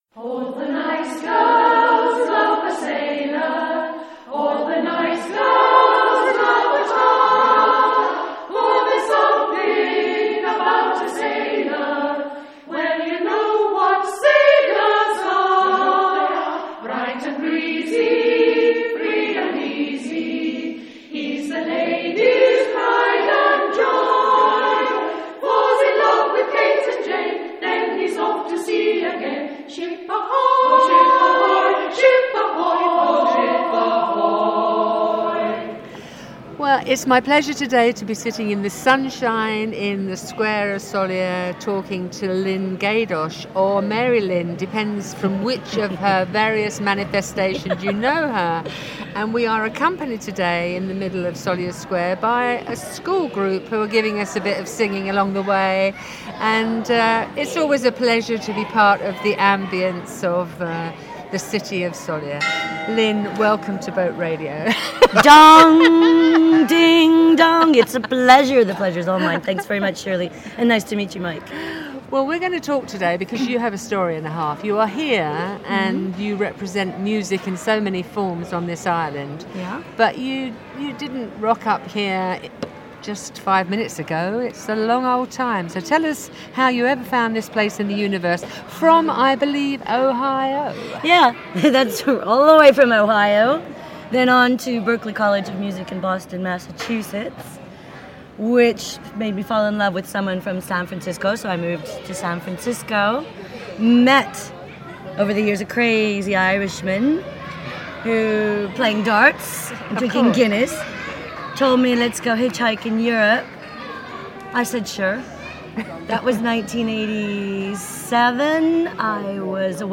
over coffee at Café Sóller in Plaça de sa Constitució